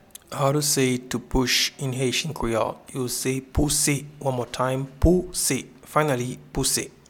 Pronunciation:
To-push-in-Haitian-Creole-Pouse.mp3